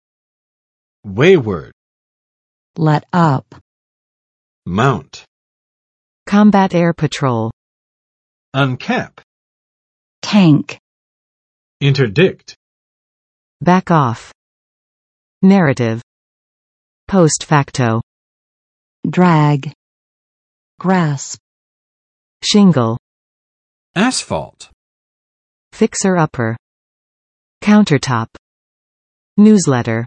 [ˋwewɚd] adj. 任性的；刚愎的，倔强的